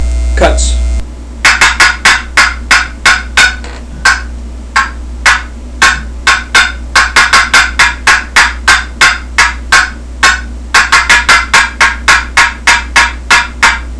Listen to 14 seconds of cutts
• Easy to use and perfect for anyone--excellent medium and high pitched hen yelps, cackles, and cutts with just the right amount of rasp.